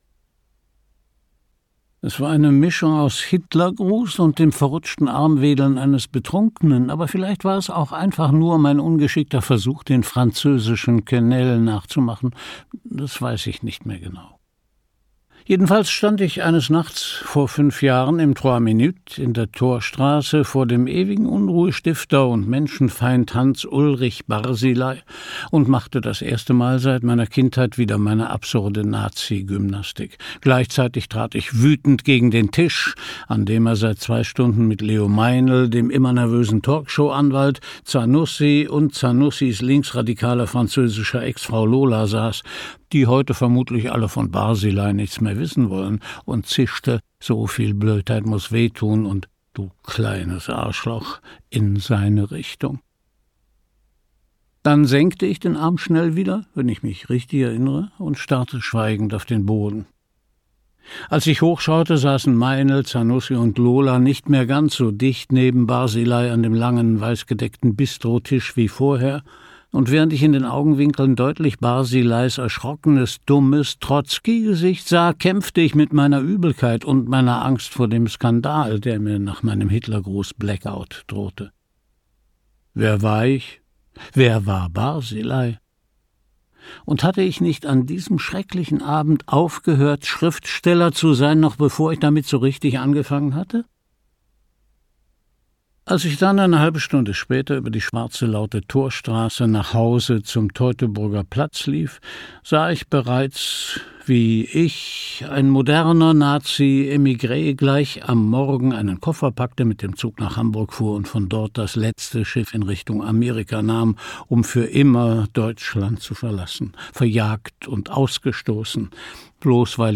Christian Brückner (Sprecher)
2021 | Ungekürzte Lesung